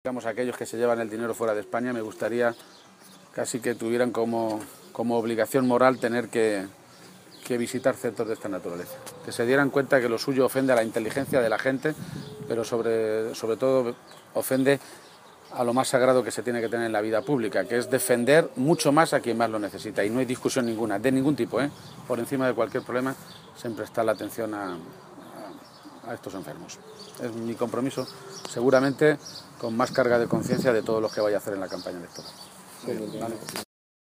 García-Page se pronunciaba de esta manera esta mañana, en Yepes, en la provincia de Toledo, donde tiene su sede y su centro de Atención AMAFI, una Asociación para el Cuidado Integral de las personas con discapacidad intelectual.
Allí ha recorrido las instalaciones acompañado por el equipo directivo y, tras finalizar la visita y en una atención a los medios de comunicación, ha sostenido que centros de este tipo «hacen que una salga más moralizado que cuando entró».